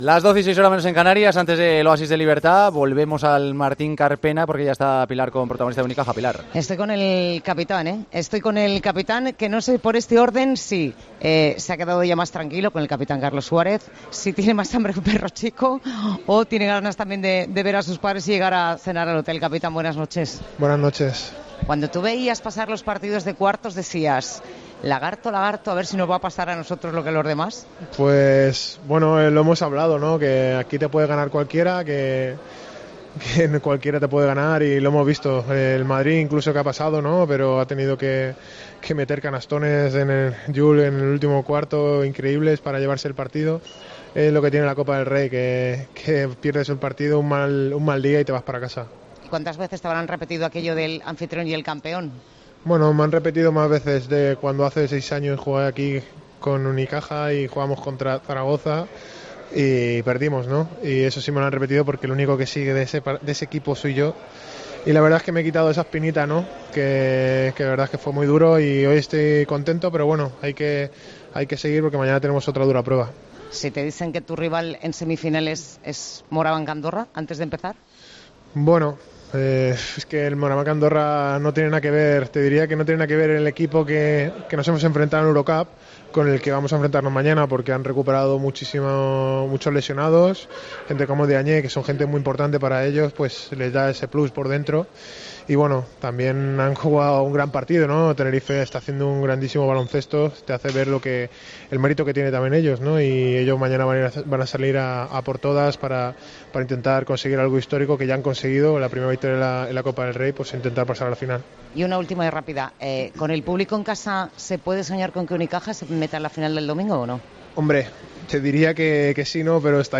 habla con el jugador del Unicaja, después del triunfo del equipo malagueño en cuartos de la Copa del Rey.